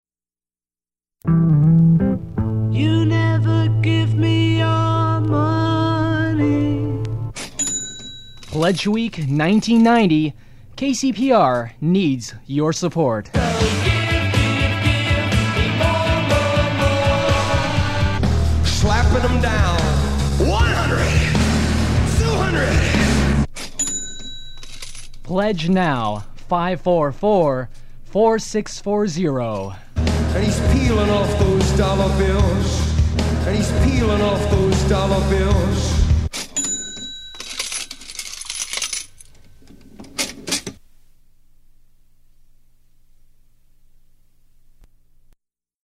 Pledge Quickies, Cash Register Ends, 40 seconds
Form of original Audiocassette